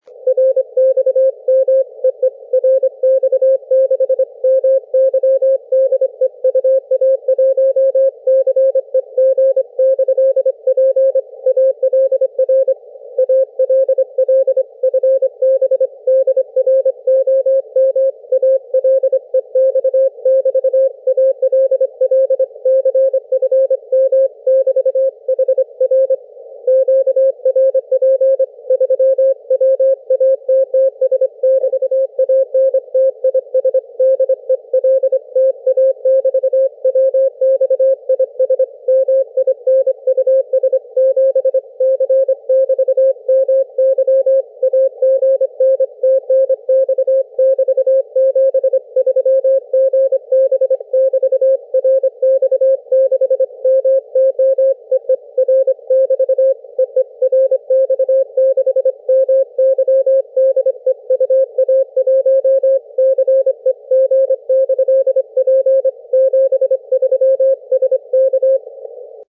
И из которого три ватта и дельточка на ветках вот так чудесно звучат за тысячи километров:
На Украине сигнал моего передатчика еле превышал шумы, а в Ростове просто отлично.